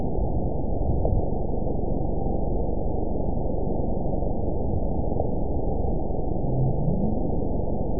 event 917304 date 03/27/23 time 09:34:08 GMT (2 years, 1 month ago) score 9.19 location TSS-AB01 detected by nrw target species NRW annotations +NRW Spectrogram: Frequency (kHz) vs. Time (s) audio not available .wav